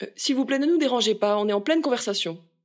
VO_ALL_EVENT_Trop proche de la cible_02.ogg